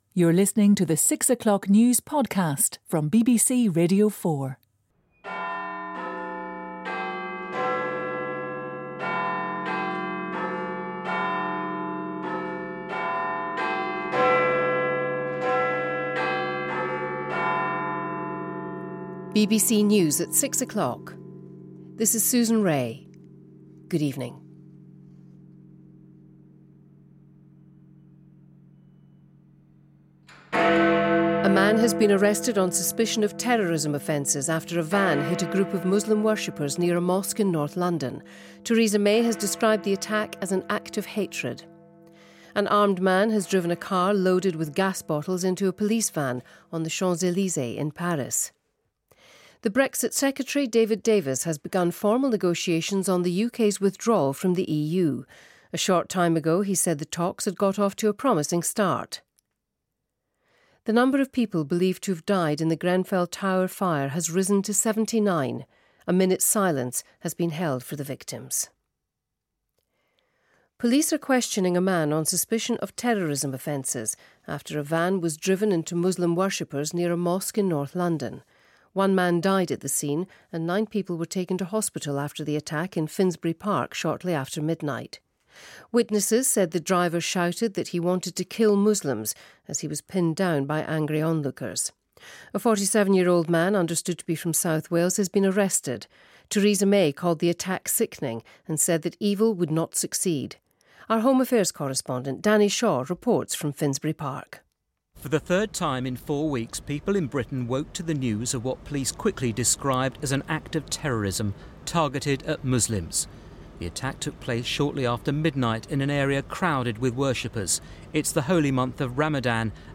You can listen to the news item HERE: